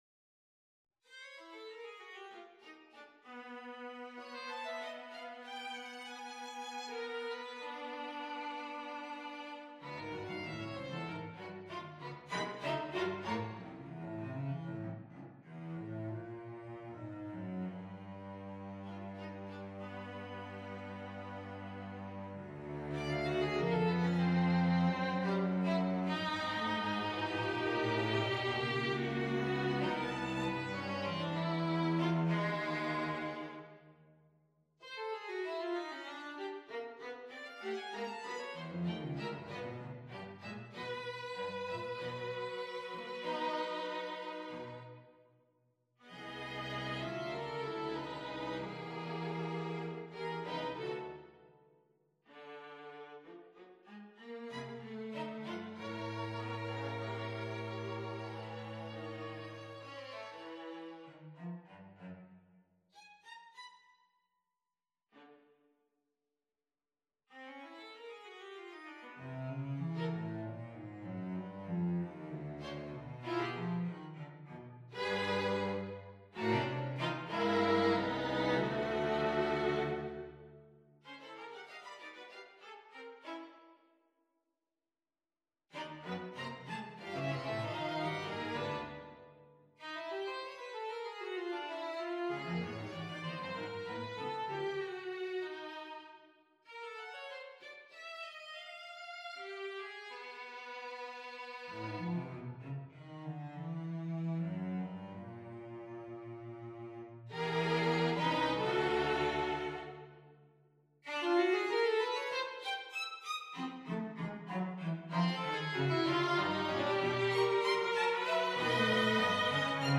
Quartet for Strings No.12 on a purpose-selected tone row Op.96 (1)Andante comodo (2)Allegro molto (3)Lento molto - Andante espressivo - Tempo Primo (4)Andante con spirito - Presto - Largo appassionato - Tempo secondo Date Duration Download 17 March 2025 19'54" Realization (.MP3) Score (.PDF) 18.2 MB 997 kB